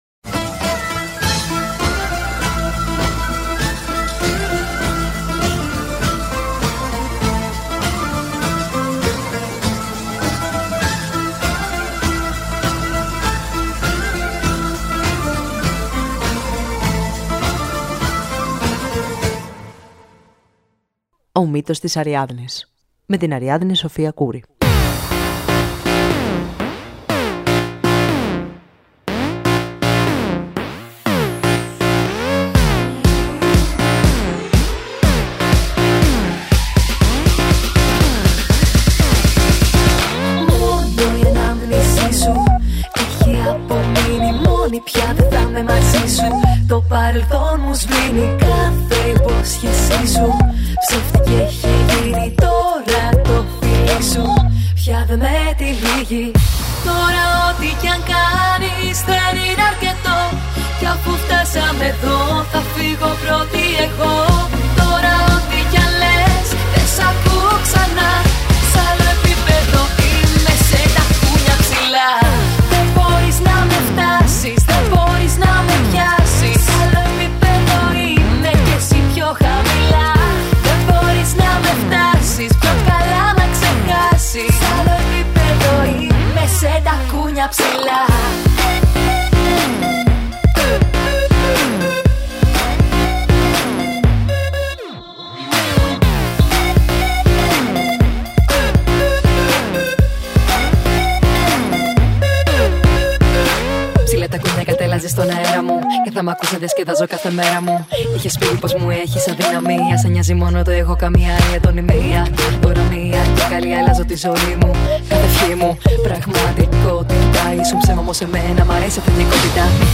Η διάσημη Ελληνίδα καλλιτέχνιδα Κατερίνα Στικούδη, σε μια ενδιαφέρουσα συζήτηση για την ζωή και τις επιλογές της.